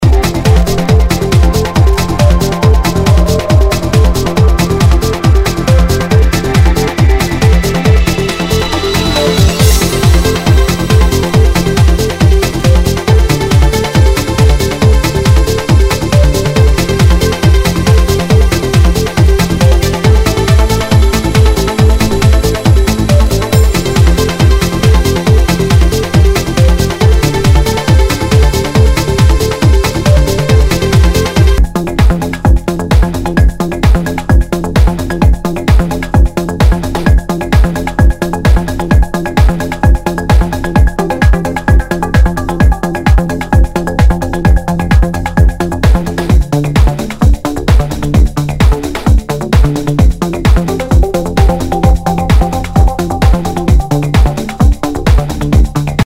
HOUSE/TECHNO/ELECTRO
ナイス！プログレッシブ・ハウス！